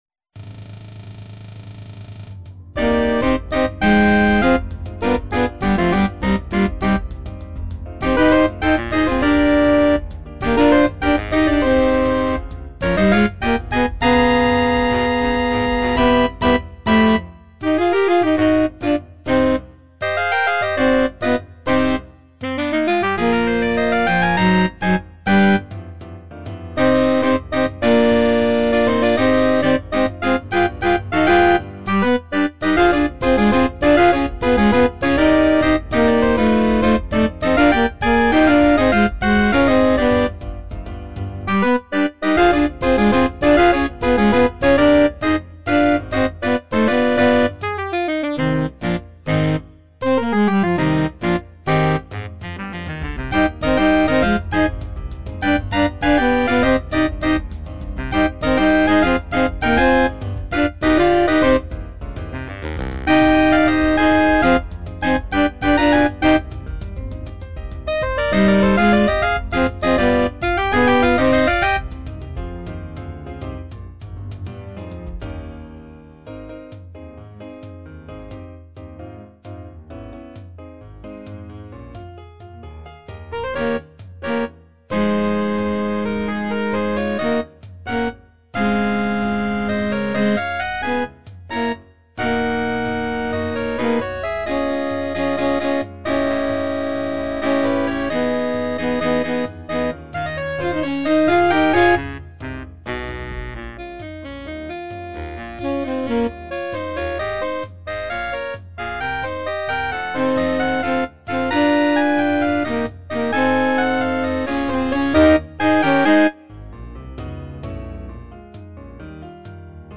Instrumentalnoten für Saxophon